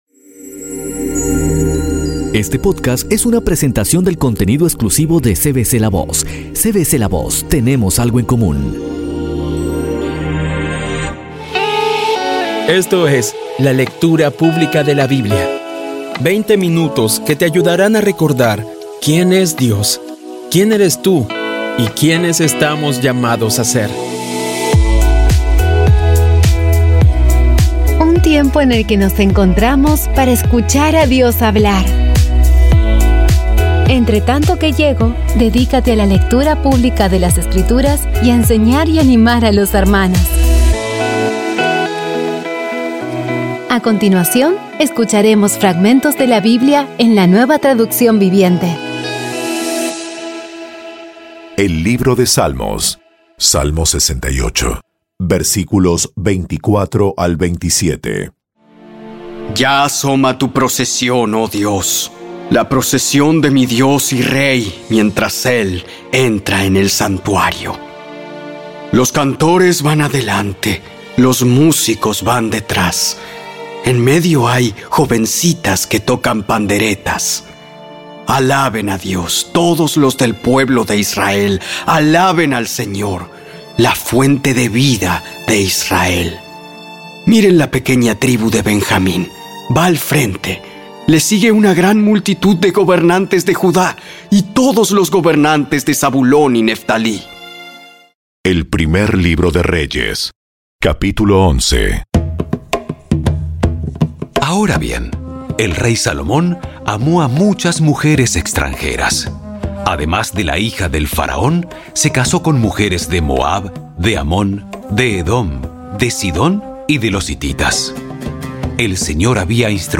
Audio Biblia Dramatizada Episodio 154
Poco a poco y con las maravillosas voces actuadas de los protagonistas vas degustando las palabras de esa guía que Dios nos dio.